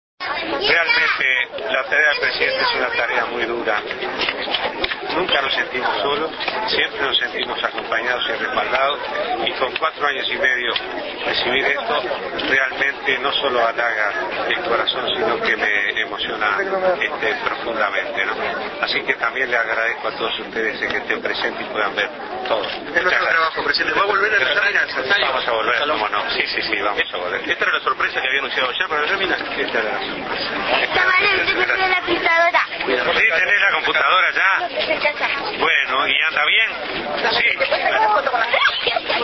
Declaraciones a la prensa del Presidente Vázquez en el Barrio las Láminas